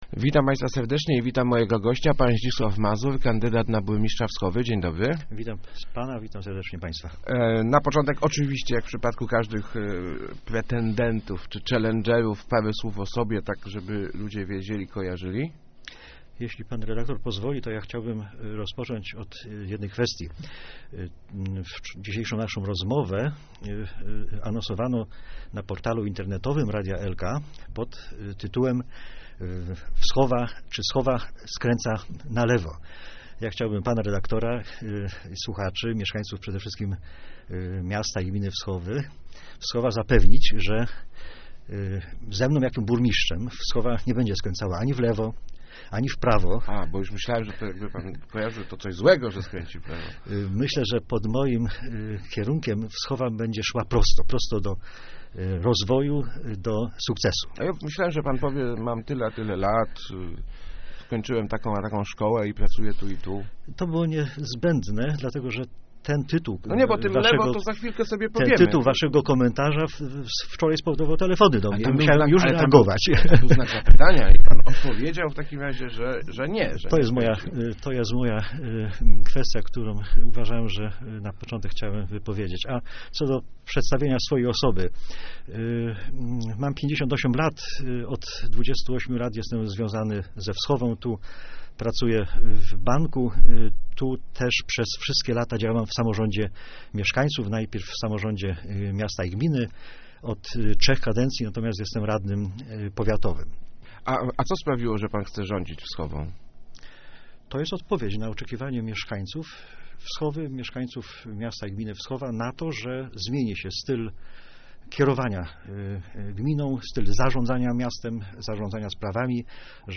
taki jest pewny wynik po wywiadzie radiowym